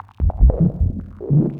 Index of /musicradar/rhythmic-inspiration-samples/150bpm
RI_ArpegiFex_150-05.wav